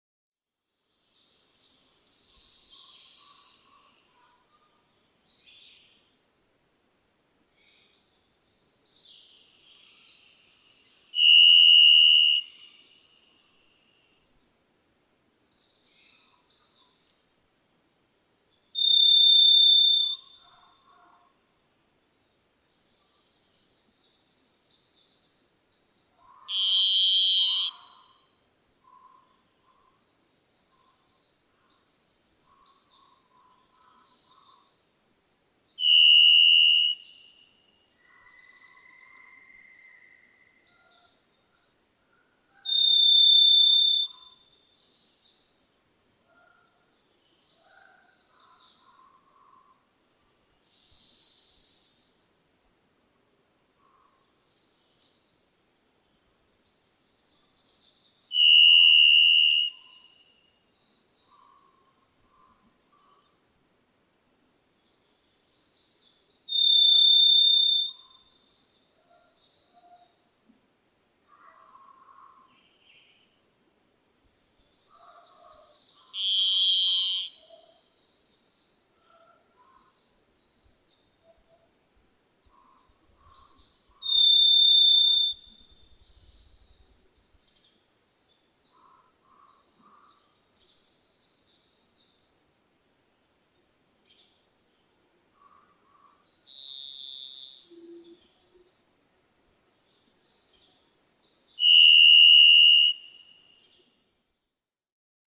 Varied thrush
Try whistling and humming at the same time, using your two sound sources to mimic the eerie songs of the varied thrush.
120_Varied_Thrush.mp3